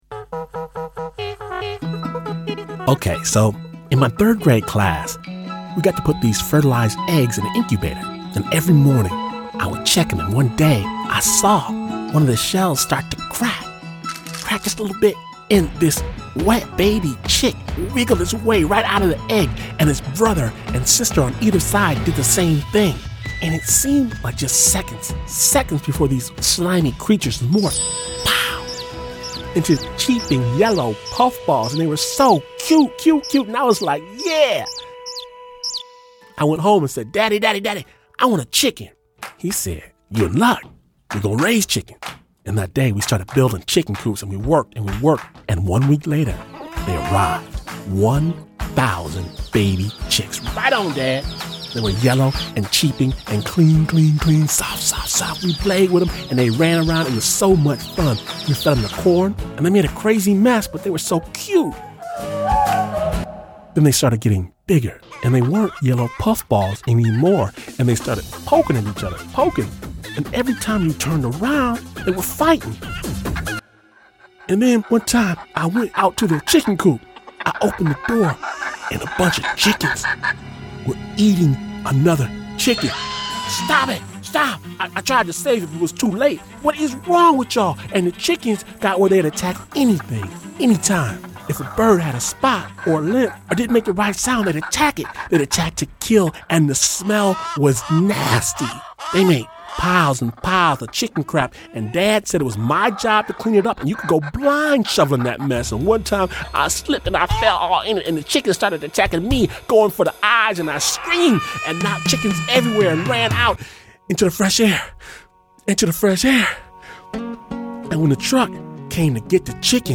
Snap Judgment (Storytelling, with a BEAT) mixes real stories with killer beats to produce cinematic, dramatic, kick-ass radio. Snap’s raw, musical brand of storytelling dares listeners to see the world through the eyes of another.